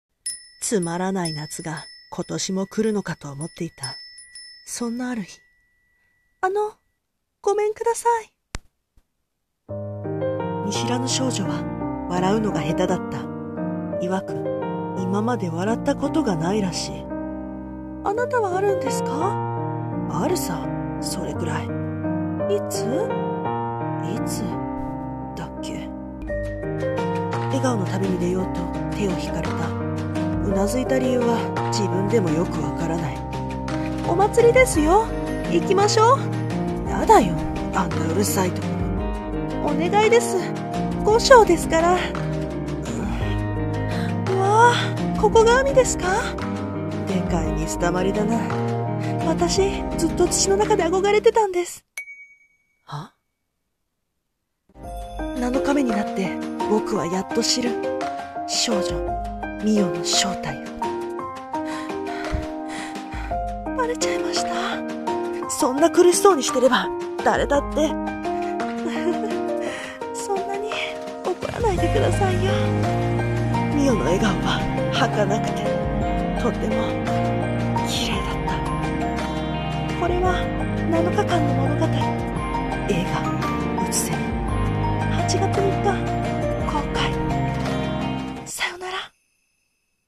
【声劇】空蝉（一人二役）